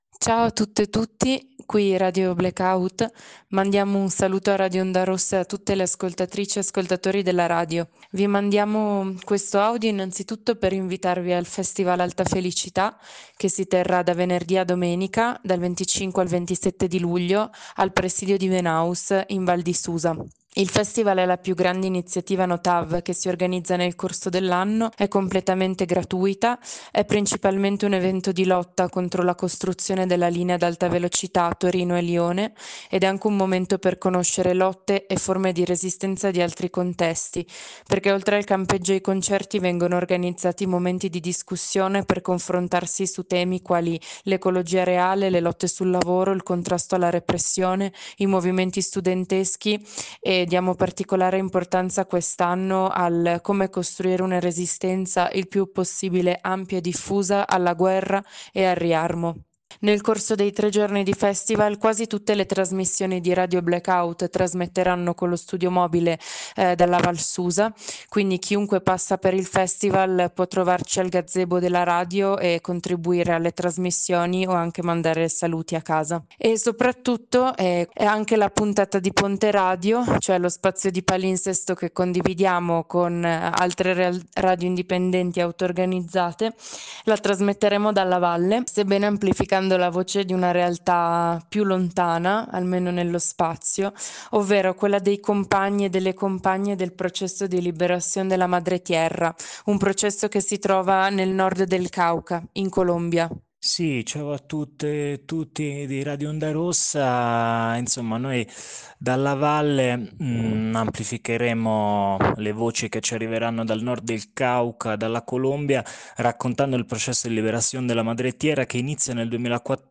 A condurre il prossimo ponte radio sarà radio Black out, venerdì 25 luglio dalle 13 alle 15 in diretta dal Festival Alta Felicità a Venaus, in Val di Susa.